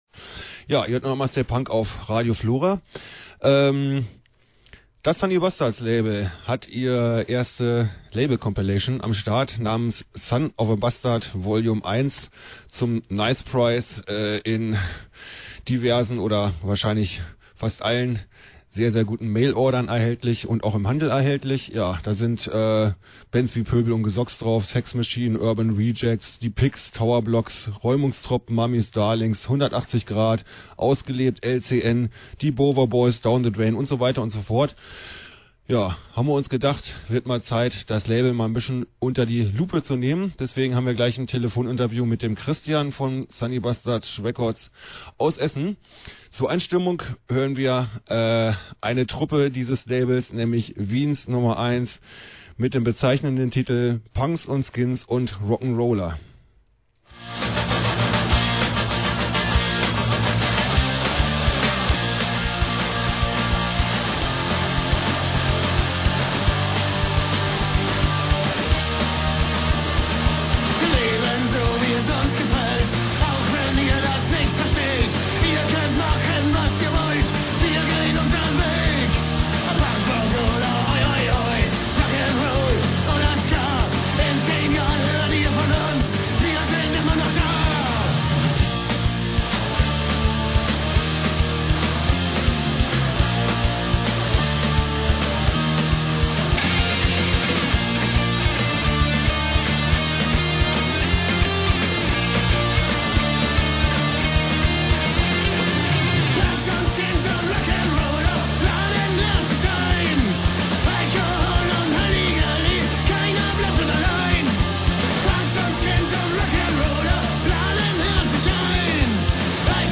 staypunk-interview.mp3